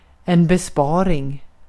Ääntäminen
Ääntäminen US Haettu sana löytyi näillä lähdekielillä: englanti Käännös Ääninäyte Substantiivit 1. besparing {en} Saving on sanan save partisiipin preesens.